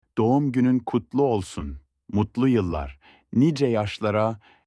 happy-birthday-in-turkish.mp3